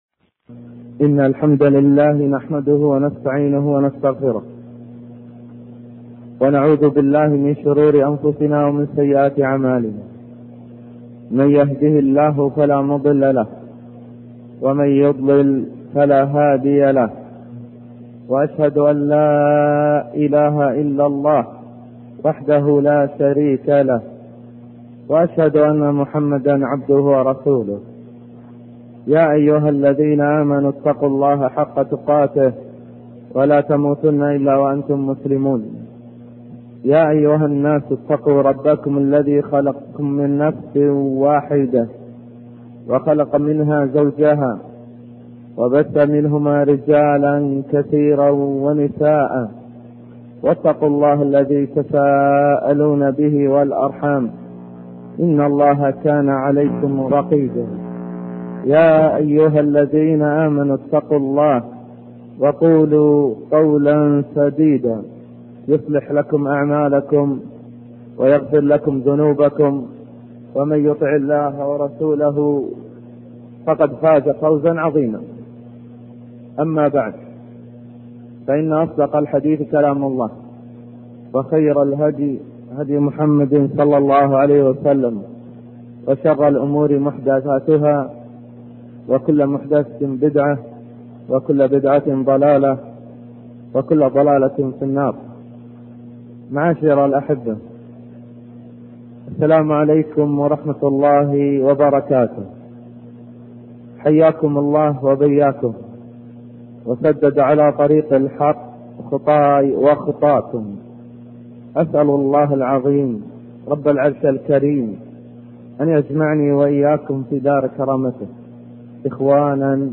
الباب الأول: مقدمة وخطبة افتتاحية